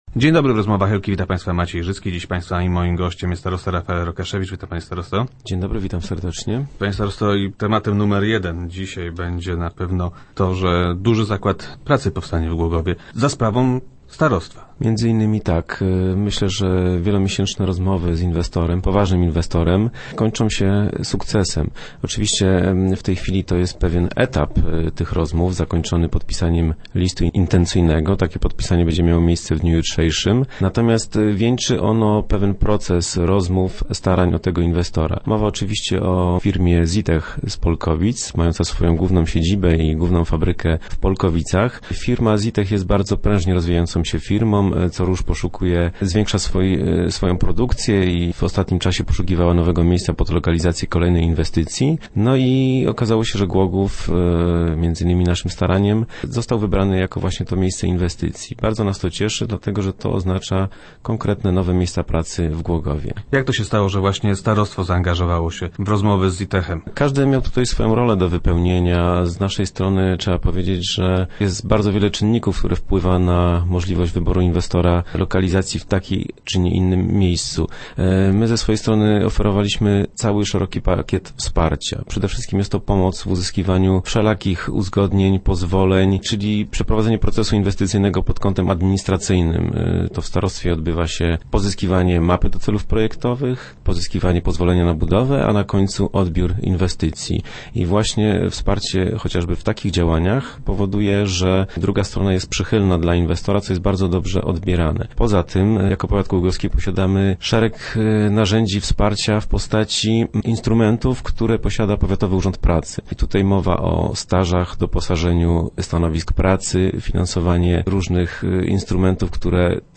- W środę nastąpi podpisanie listu intencyjnego w tej sprawie – informuje starosta Rafael Rokaszewicz, który był gościem Rozmów Elki.